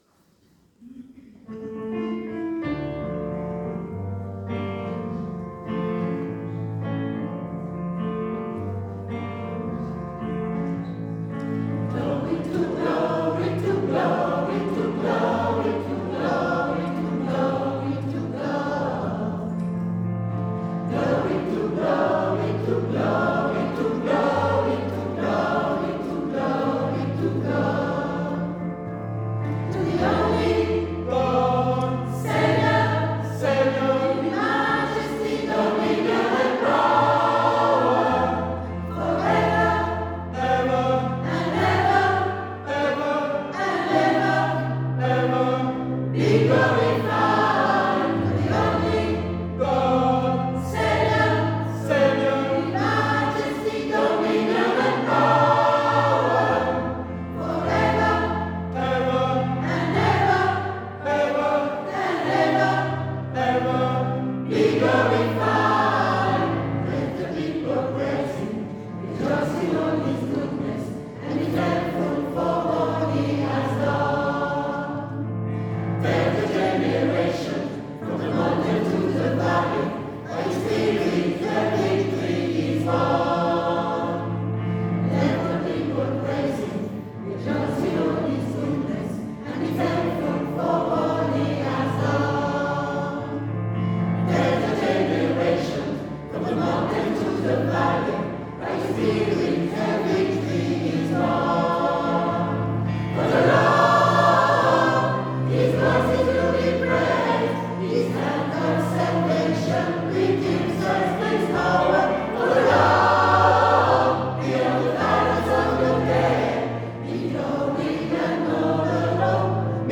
Elle se compose d'une trentaine de choriste
Les chants sont principalement en anglais, et sont chantés par chœur, sans livret ni prompteur.
Le Garden Gospel Group s'est produit à Jardin, à Reventin puis à Sainte-Colombe pour ses 3 concerts de fin d'année.